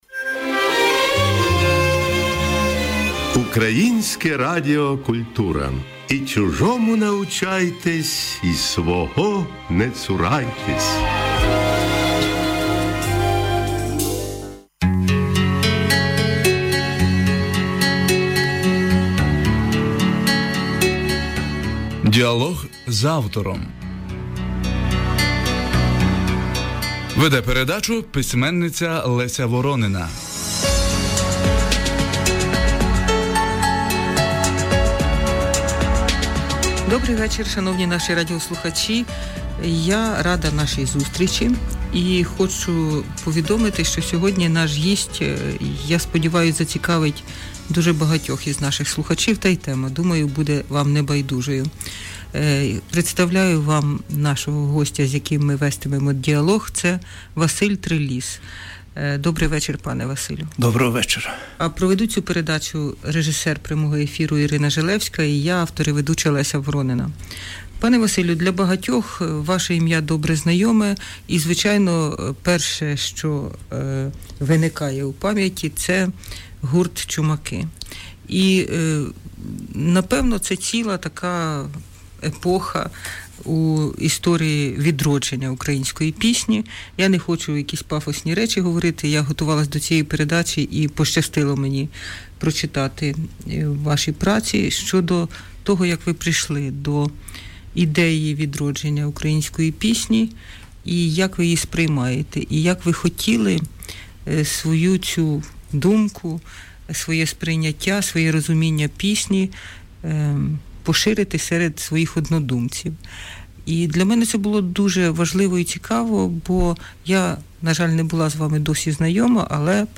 3 канал "Культура" Українського Радіо, запис прямого ефіру, програма "Знакова постать", 2010-і. – 50 хв.